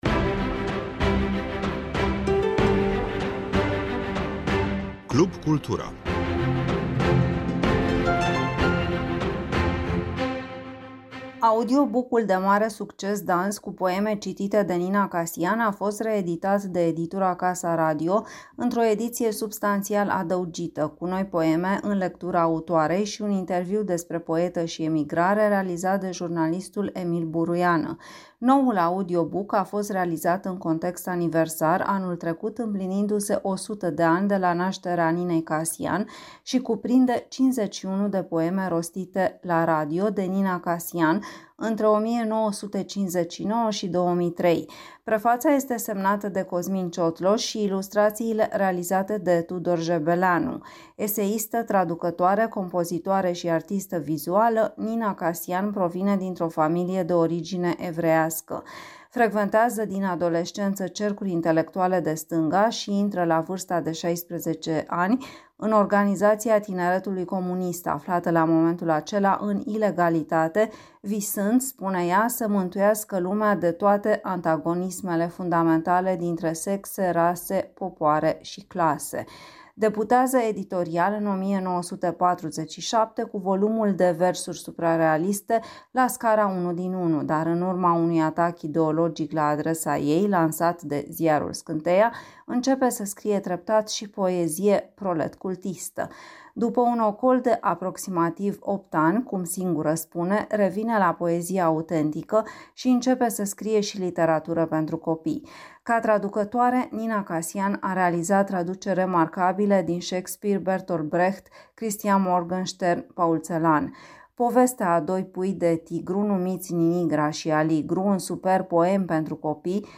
au participat la lansarea organizată de Editura Casa Radio